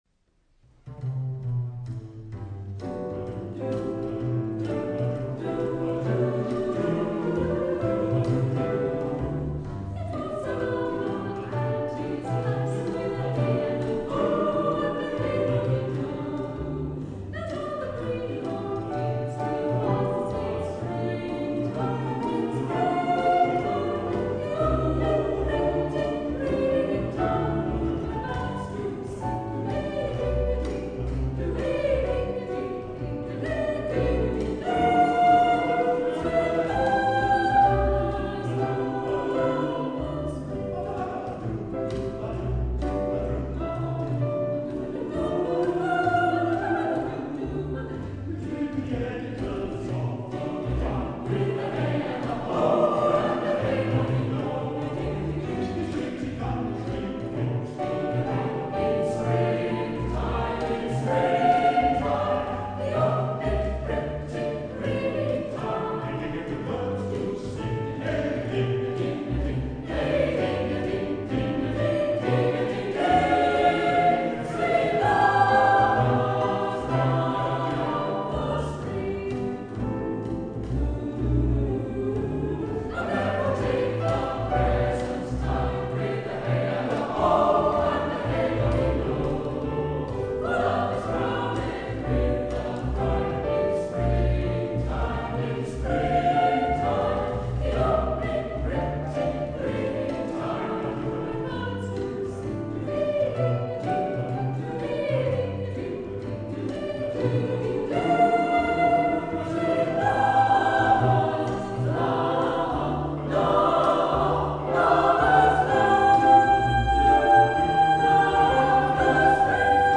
Soprano Alto Tenor Bass